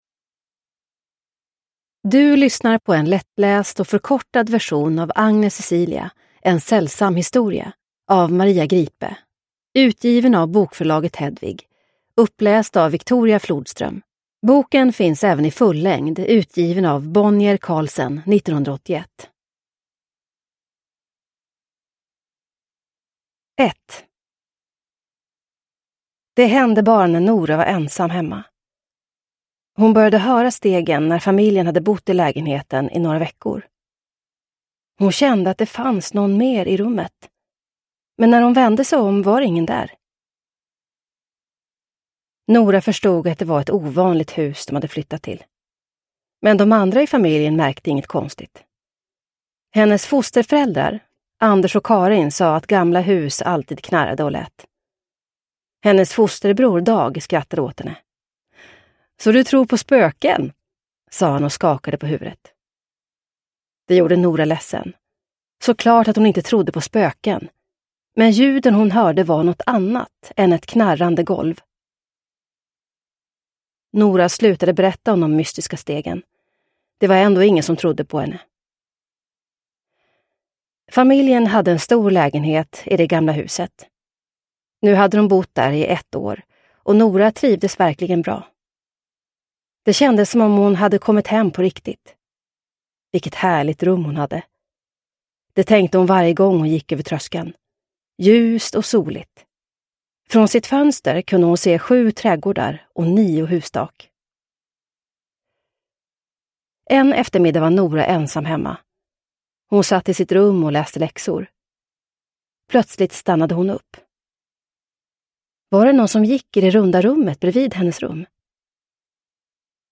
Agnes Cecilia : en sällsam historia (lättläst) – Ljudbok